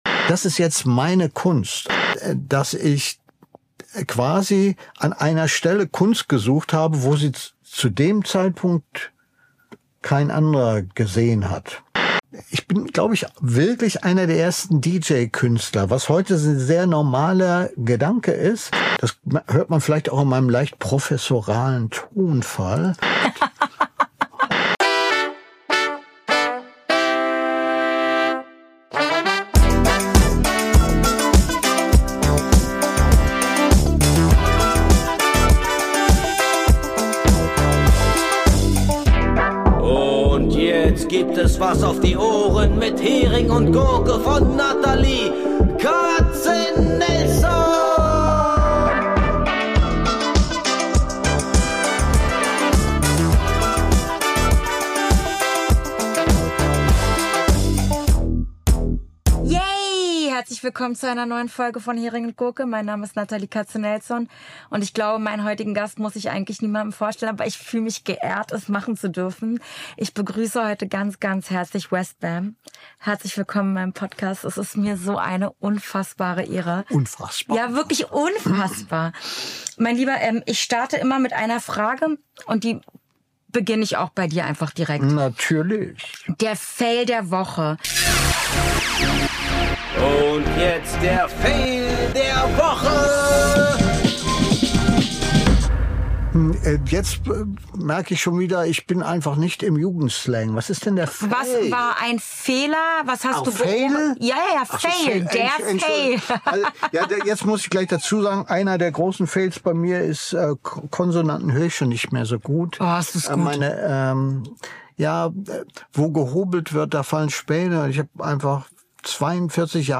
WestBam, Dj Westbam, der eigentlich nie stehen geblieben ist, öffnet Türen, die man als Publikum sonst nie sieht – und spricht über das, was man hinter den Turntables nicht hört. Der Rest passiert im Gespräch.